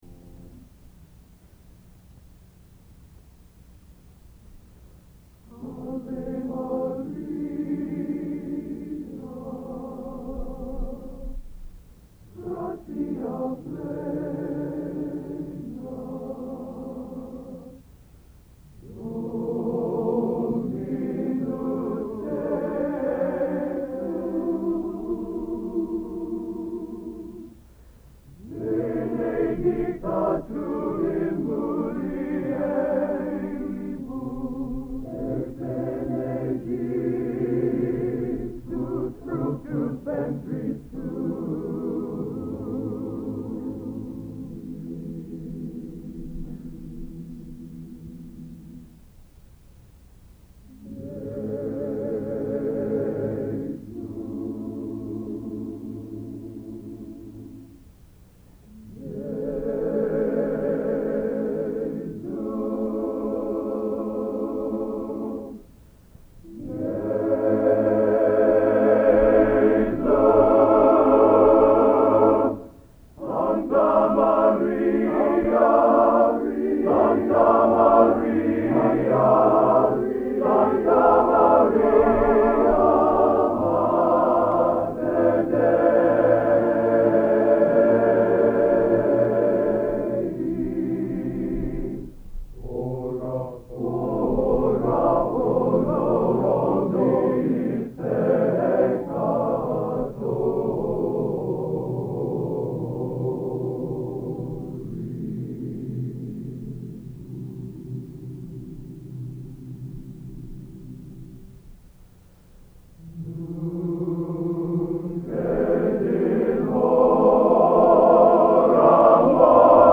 Location: West Lafayette, Indiana
Genre: Sacred | Type: End of Season